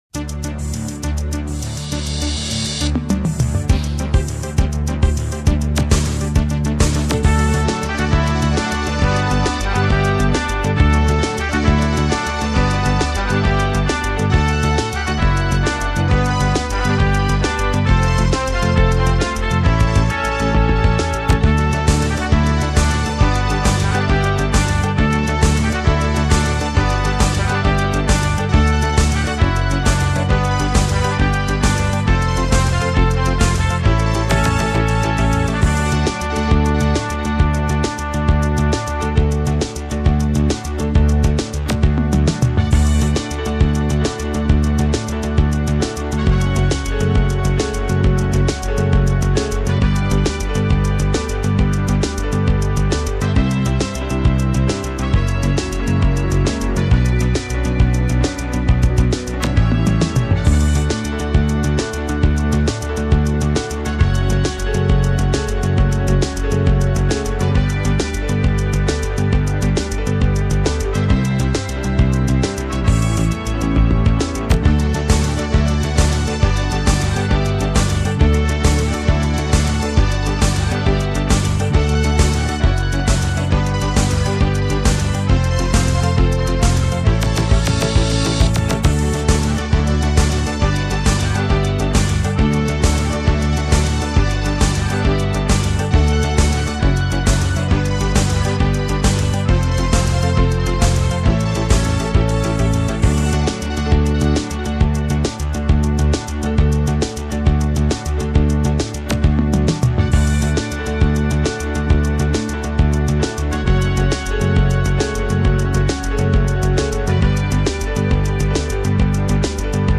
Минусовки: